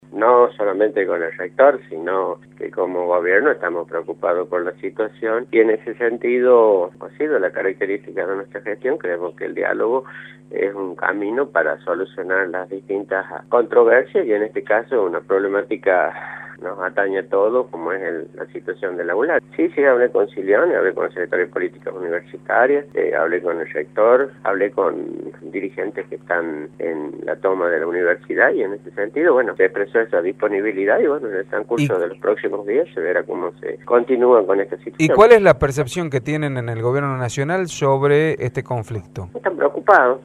“Creemos que el diálogo es el camino para solucionar las distintas controversias”, dijo a Radio La Red el funcionario.